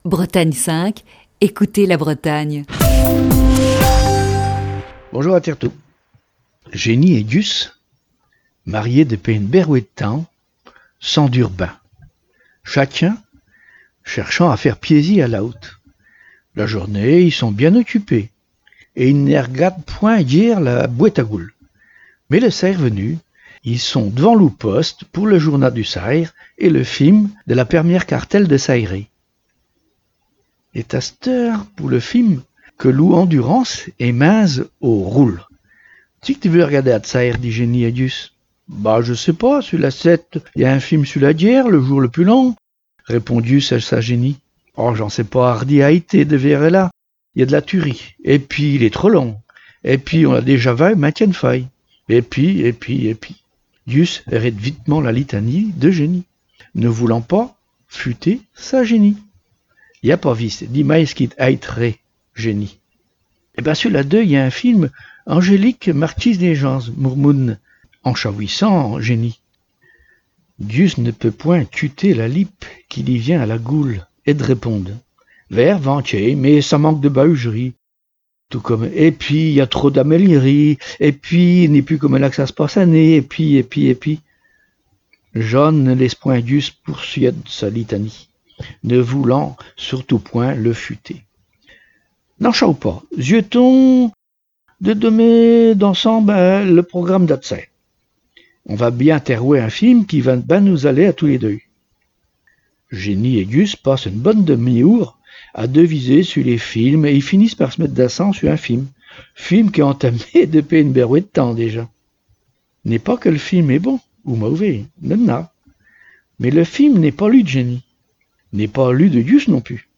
Chronique du 15 avril 2020.